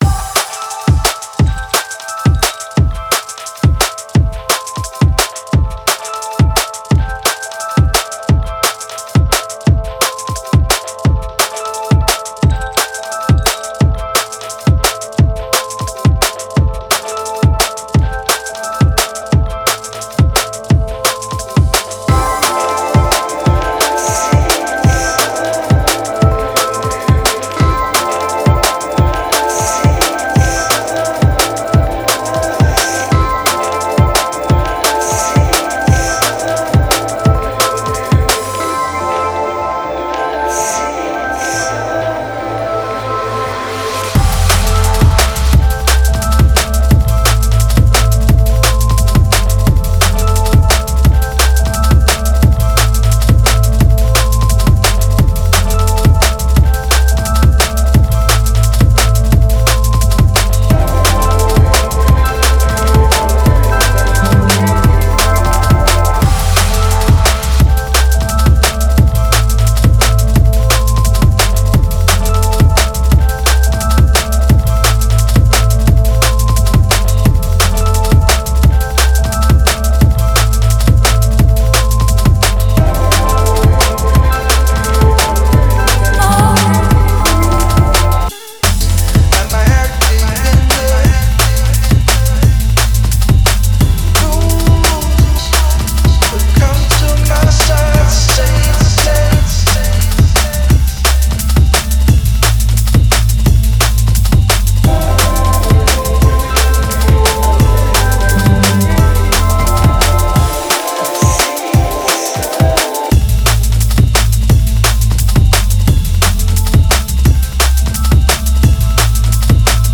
Стиль: Drum & Bass